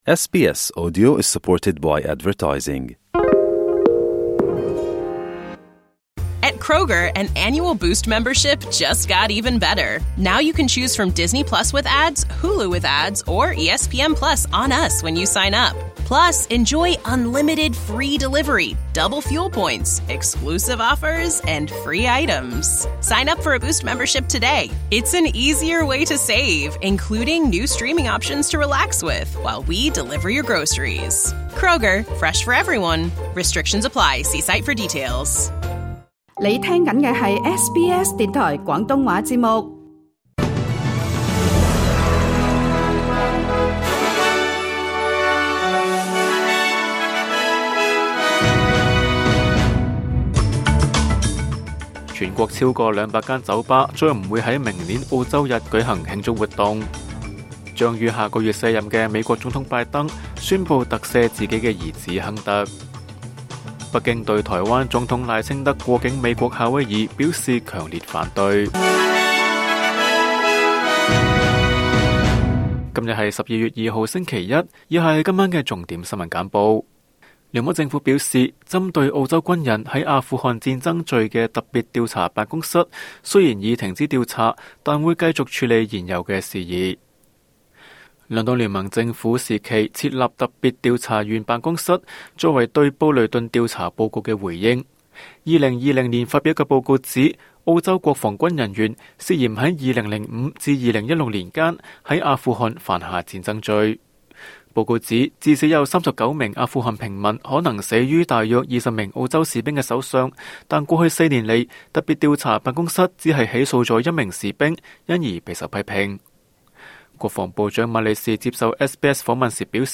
SBS 晚間新聞（2024年12月2日）
請收聽本台為大家準備的每日重點新聞簡報。